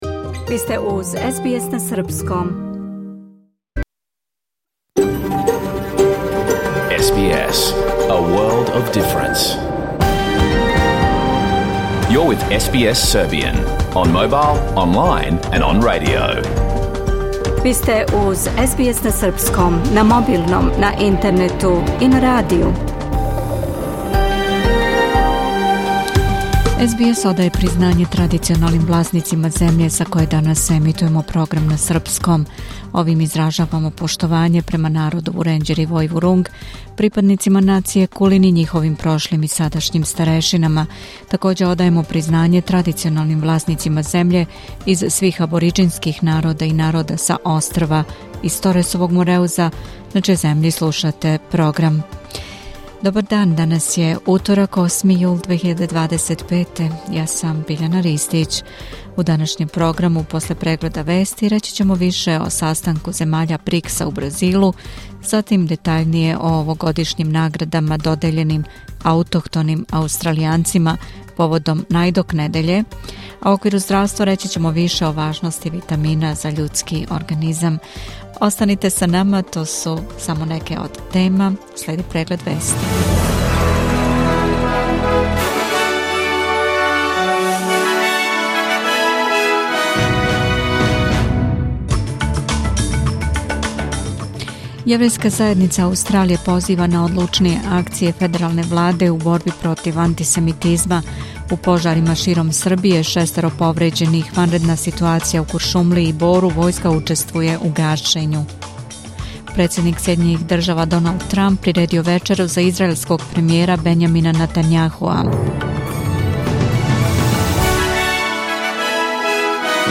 Програм емитован уживо 8. јула 2025. године